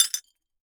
GLASS_Fragment_03_mono.wav